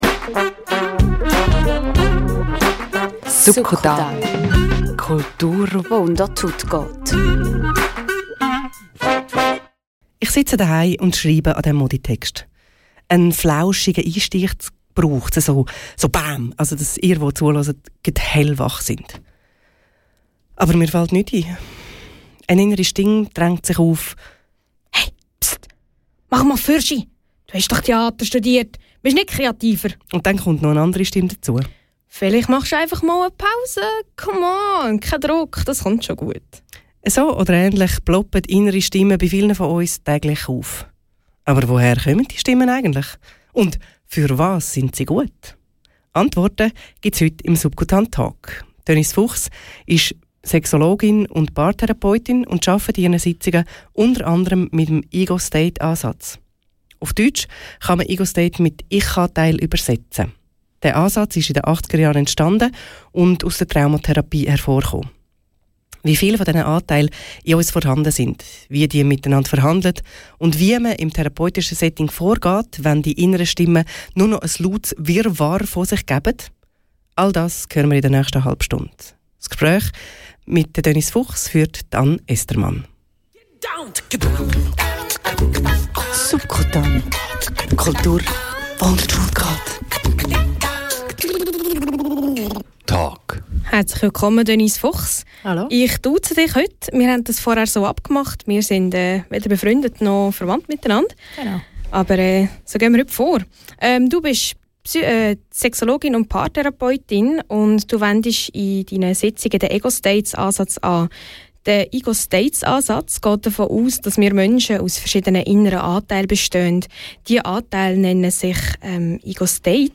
Subkutan Talk: Wie viele bin ich ~ Radio RaBe Podcast